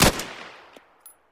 hk53_fire.3.ogg